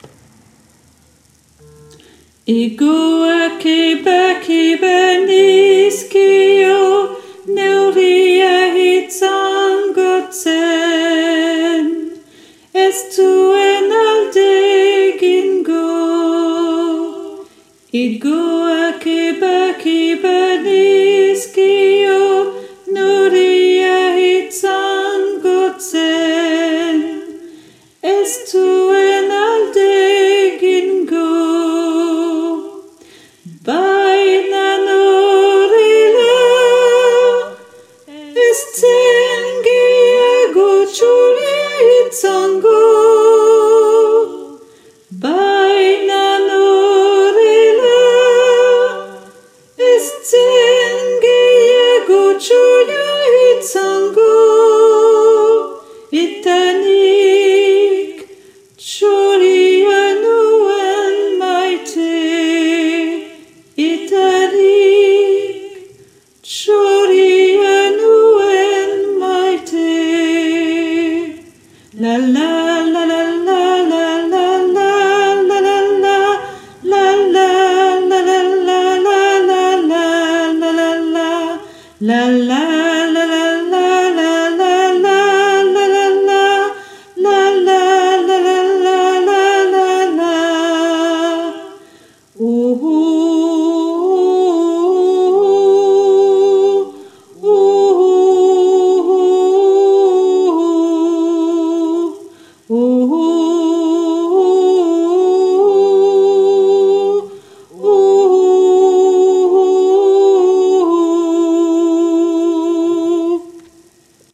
- Chant pour choeur à 4 voix mixtes (SATB)
soprano et autres voix en arrière-plan (version chantée)